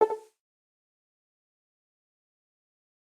Fallout New Vegas Message Window Efecto de Sonido Descargar
Fallout New Vegas Message Window Botón de Sonido
Games Soundboard31 views